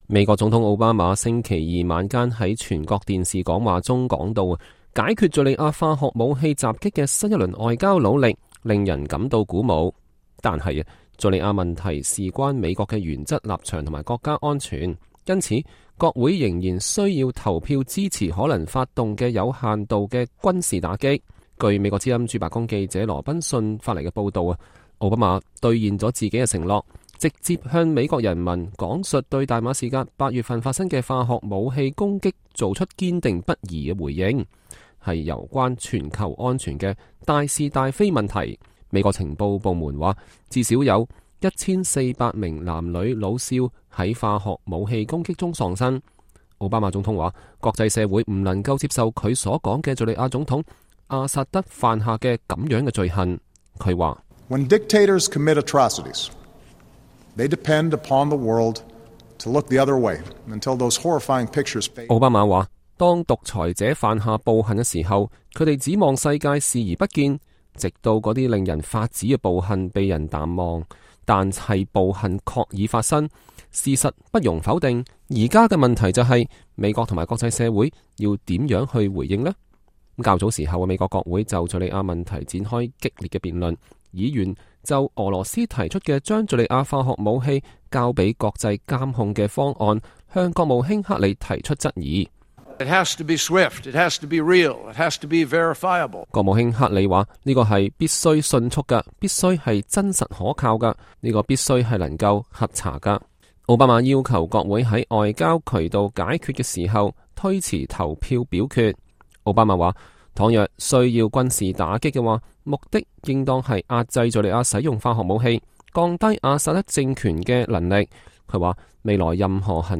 奧巴馬總統星期二在全國電視講話中說，解決敘利亞化學武器襲擊的新一輪外交努力“令人感到鼓舞”，但是，敘利亞問題事關美國的原則立場和和國家安全，因此國會仍需投票支持可能發動的有限的軍事打擊。